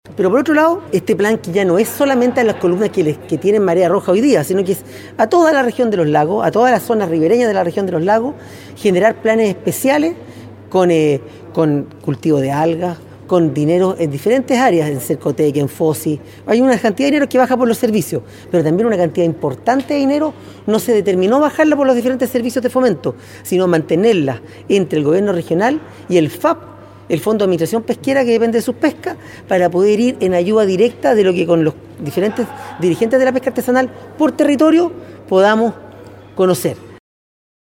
El Intendente de la Prida explicó los ejes del plan Estratégico de Monitoreo Integral y Diversificación Productiva, que considera la inversión de 9 mil 394 millones de pesos.